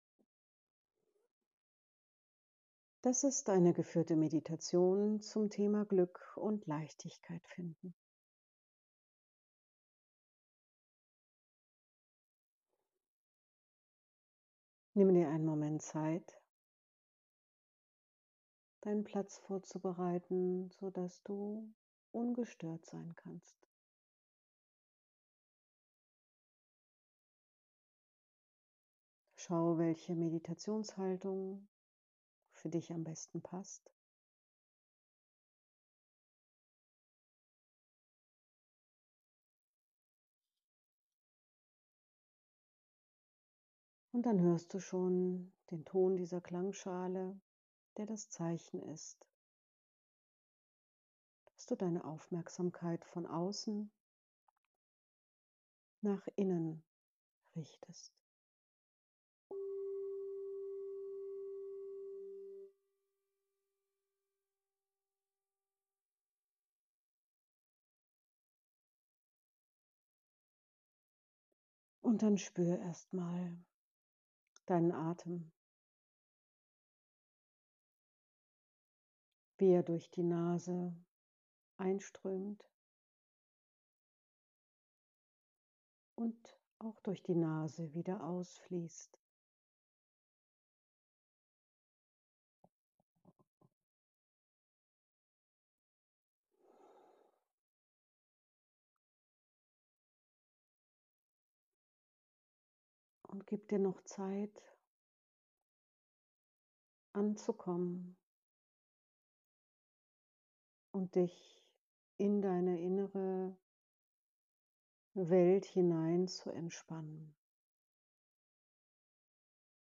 Meditation Glück und Leichtigkeit finden